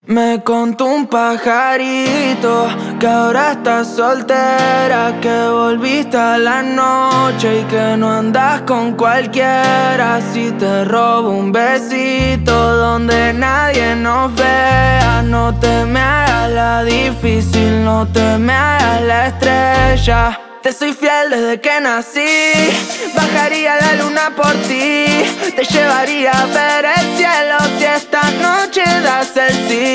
# Salsa and Tropical